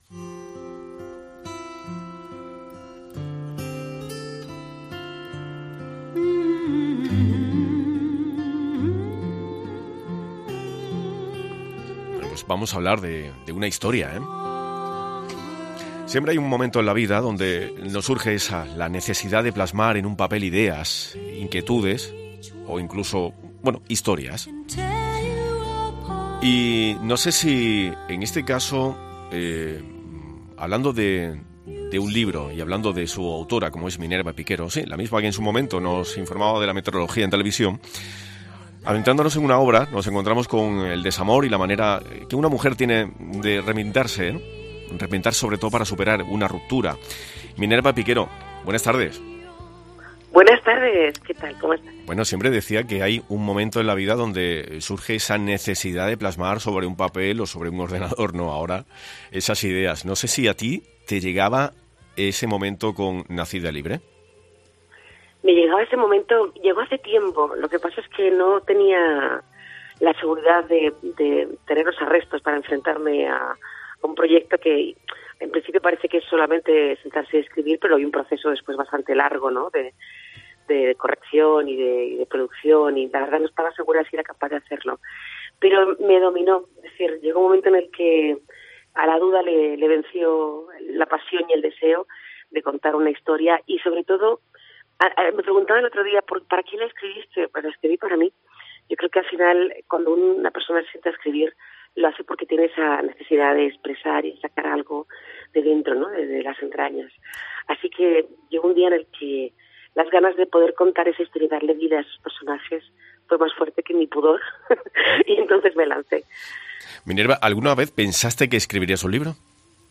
Hemos conversado con Minerva Piquero sobre "Nacida Libre", una novela con un grito de guerra.